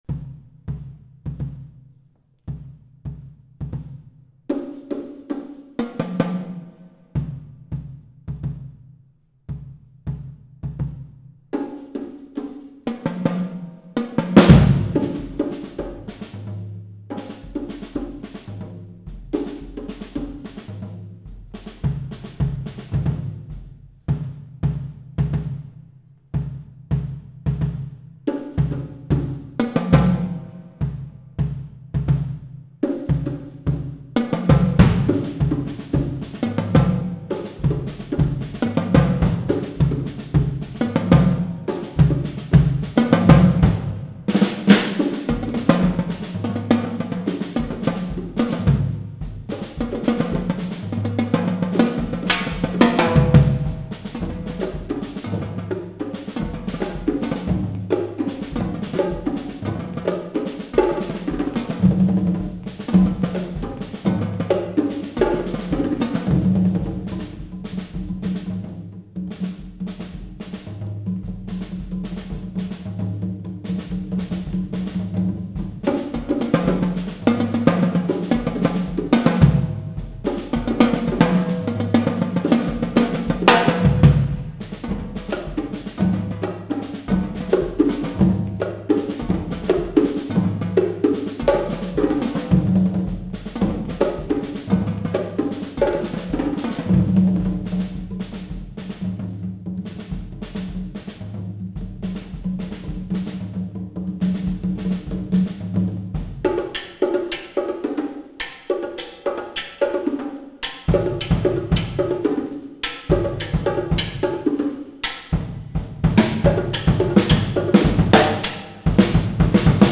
Genre: Percussion Ensemble
# of Players: 3
with hands (performer stands while playing)